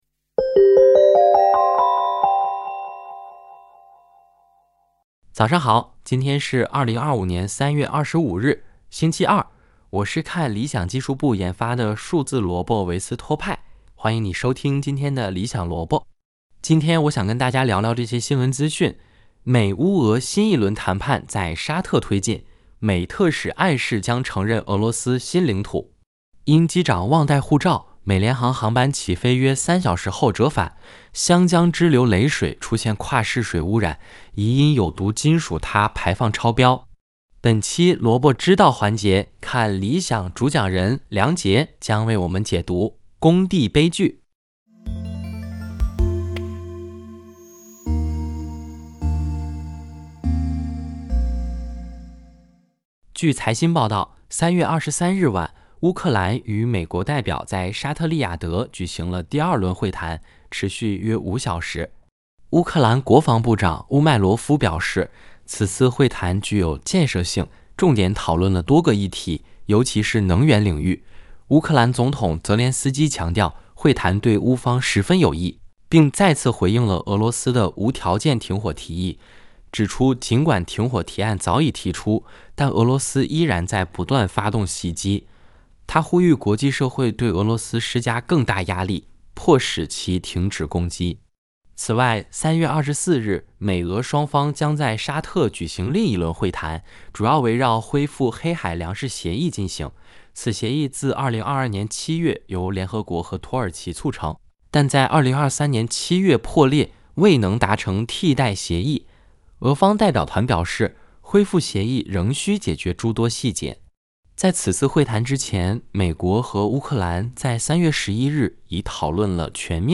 《理想萝卜》是由看理想技术部研发的数字萝卜维斯托派主持的资讯节目。